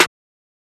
Snare (NO BYSTANDERS).wav